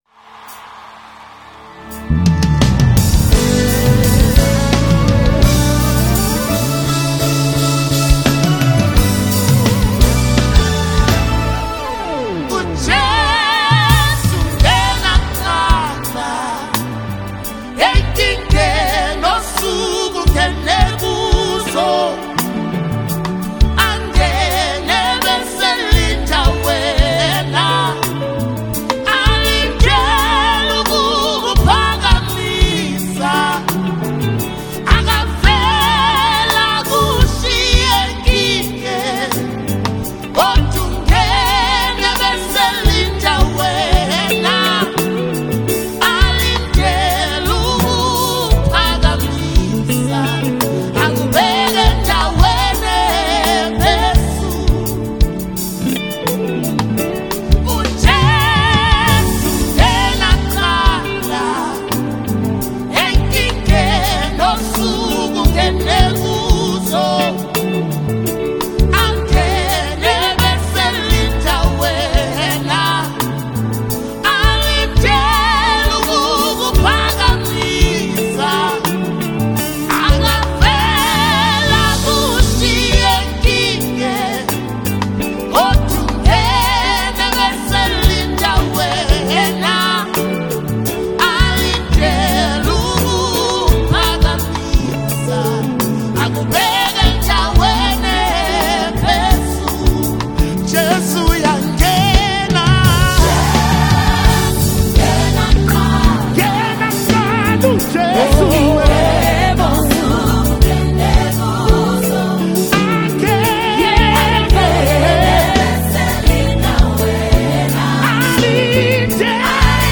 GENRE: South African Gospel.